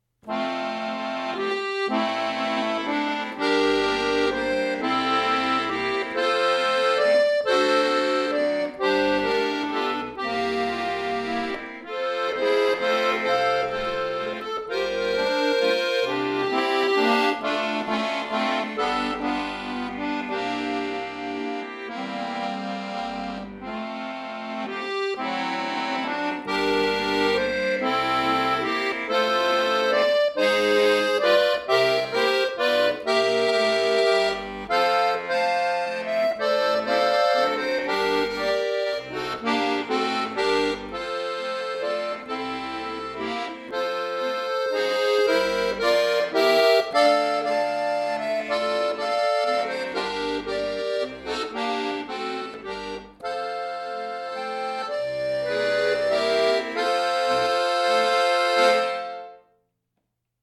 Romantischer Walzer